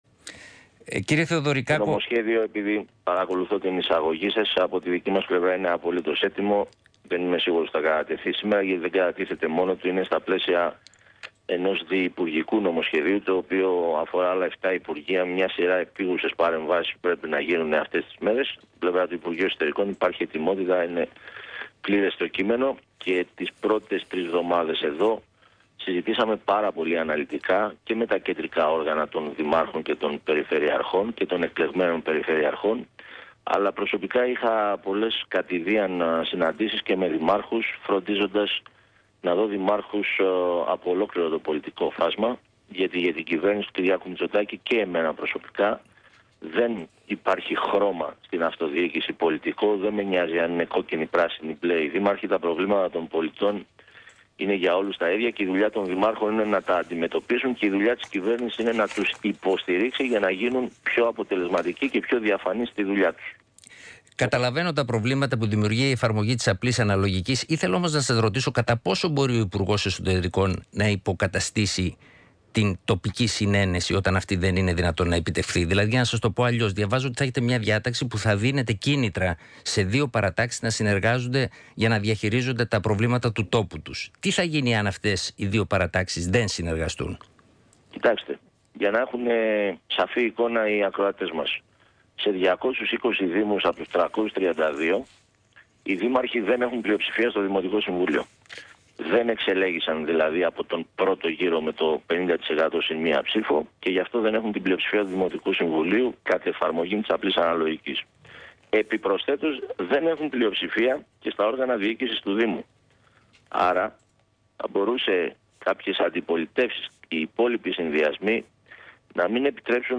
Συνέντευξη στον ραδιοφωνικό σταθμό ΣΚΑΪ 100,3 και στον δημοσιογράφο Τάσο Τέλλογλου παραχώρησε το πρωί της Δευτέρας, 29 Ιουλίου 2019, ο Υπουργός Εσωτερικών Τάκης Θεοδωρικάκος.